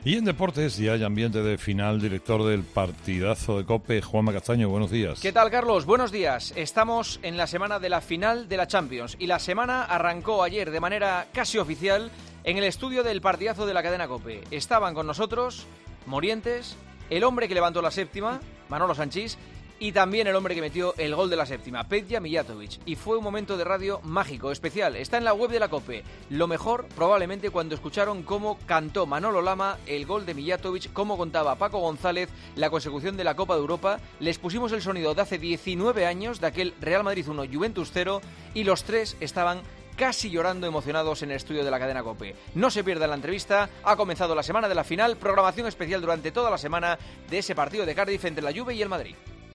La previa de la final de Champions, con la entrevista a Sanchís, Morientes y Mijatovic en 'El Partidazo', en la actualidad deportiva con Juanma Castaño.